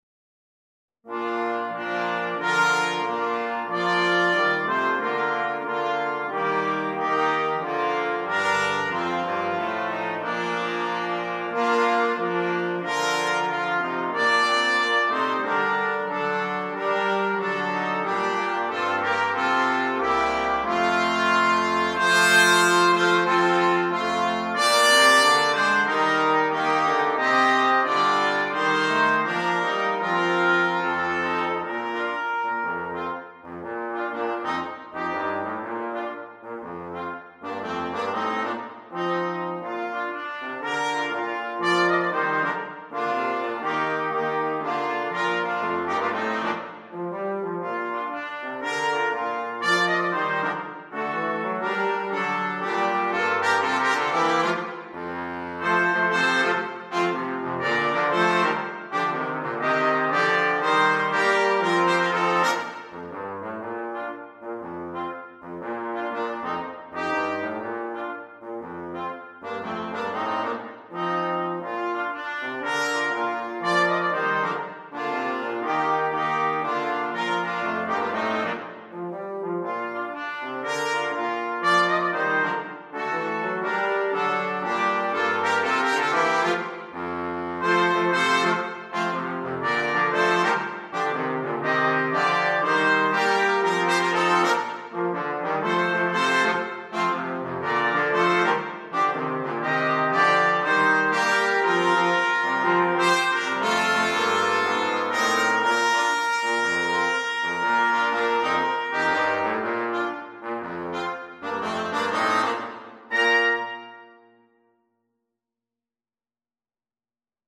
Rock and Pop